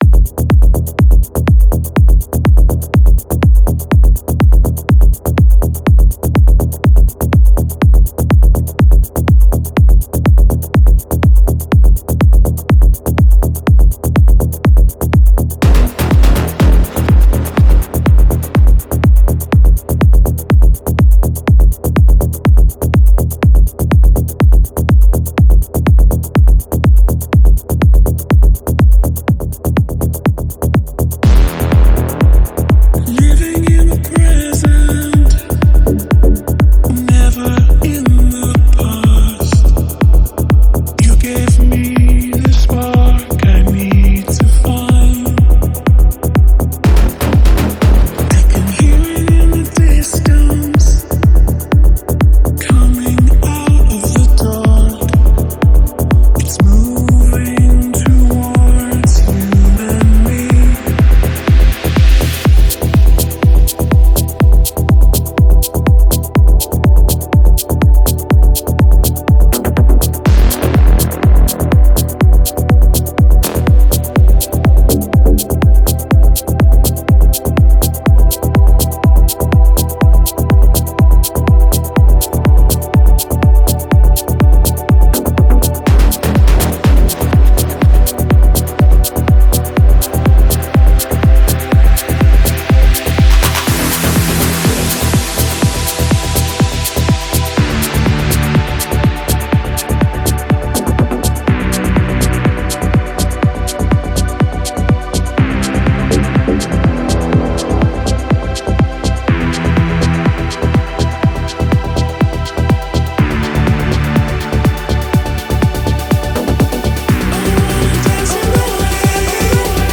• Жанр: House, Techno